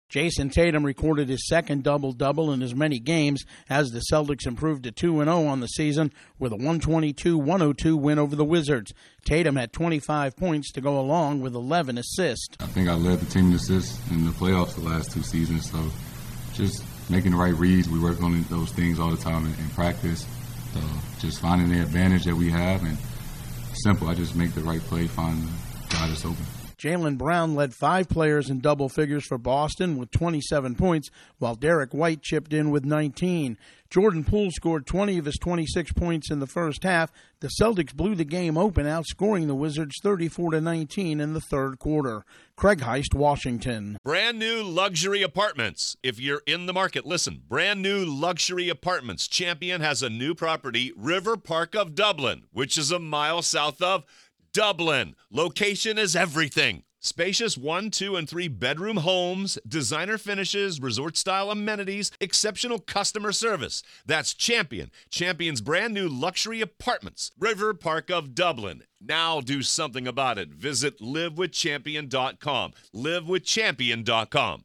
The Celitcs post their second blowout win to start their NBA title defense. Correspondent